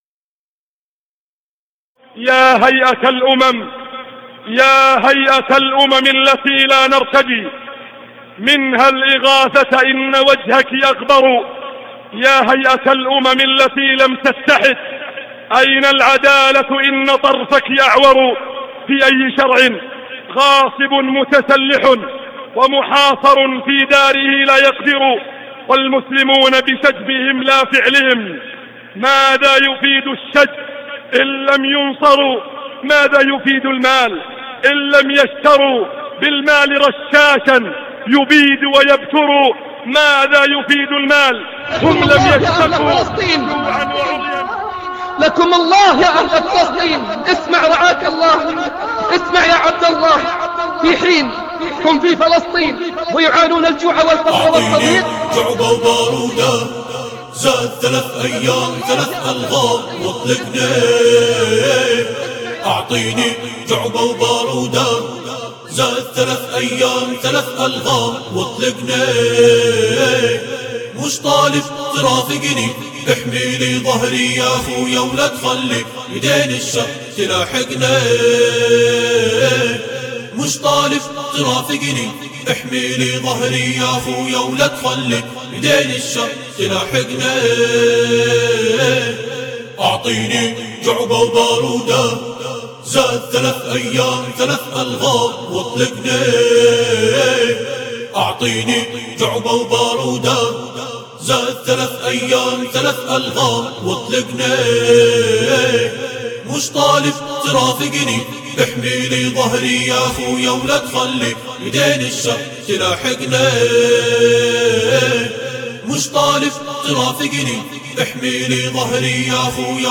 أبدع منشدنا في تقليد الصوت واللحن